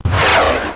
feuerwerk_zisch.mp3